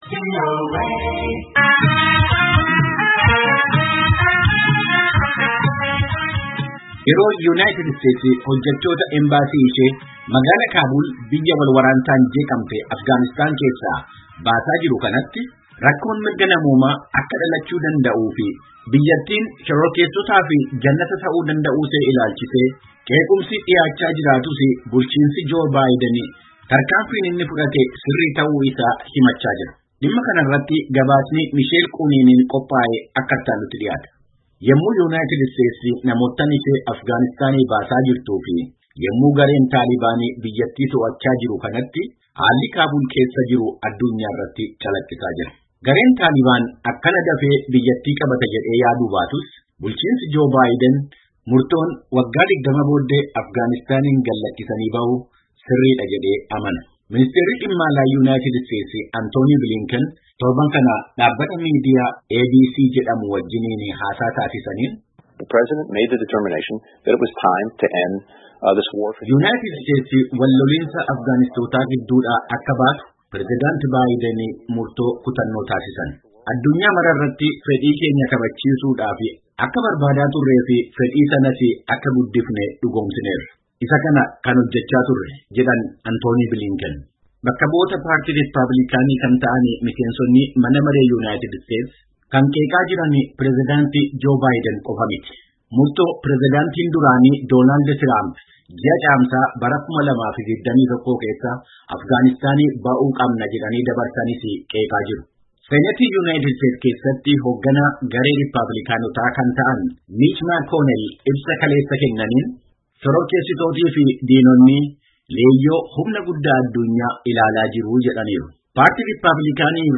Gabaasaa guuruu caqasaa.